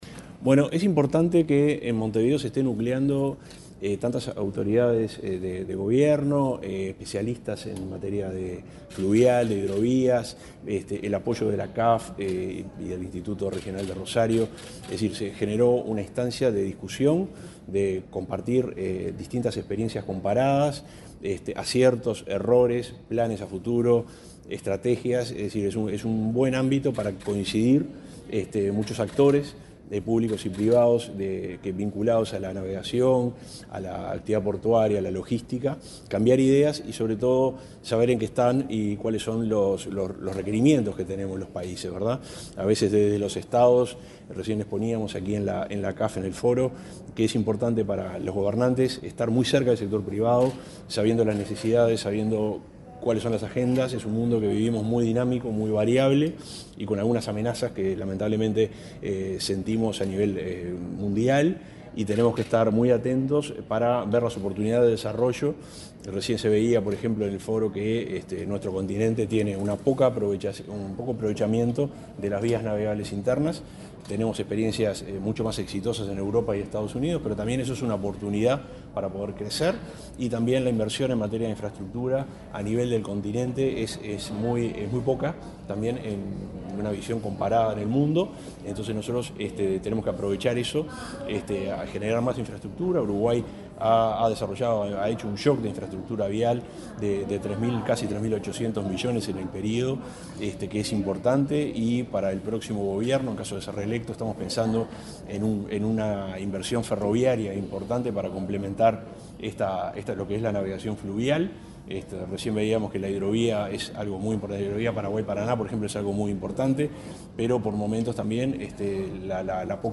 Declaraciones del subsecretario de Transporte, Juan José Olaizola
Declaraciones del subsecretario de Transporte, Juan José Olaizola 14/08/2024 Compartir Facebook X Copiar enlace WhatsApp LinkedIn El subsecretario de Transporte, Juan José Olaizola, participó, este miércoles 14, en la sede del CAF - Banco de Desarrollo de América Latina y el Caribe, en la apertura del primer Foro Fluvial Sudamericano. Luego dialogó con la prensa.